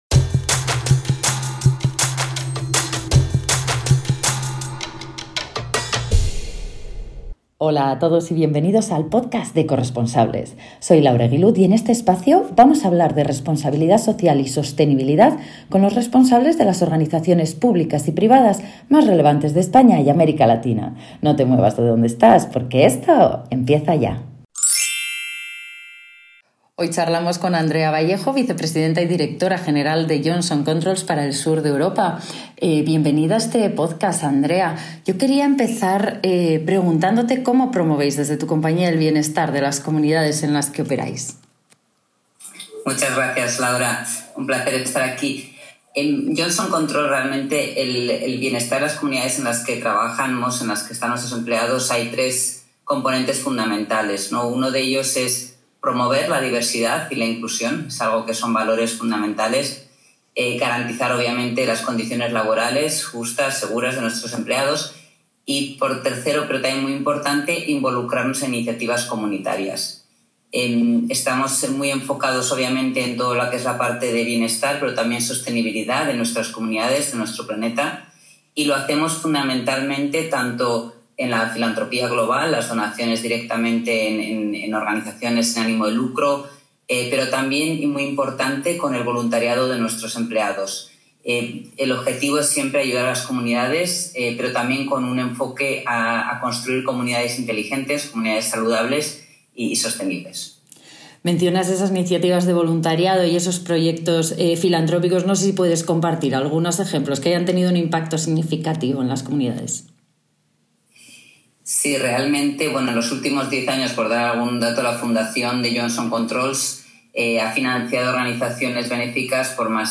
Entrevista
Entrevista realizada